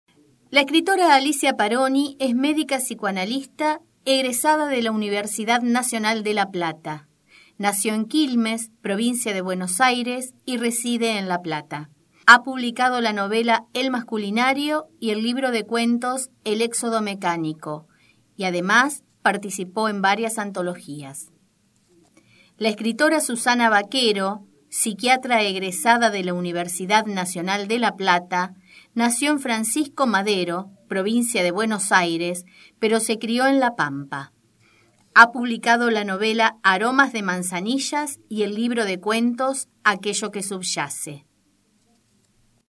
Palabras claves: Narración de cuentos ; Audiolibro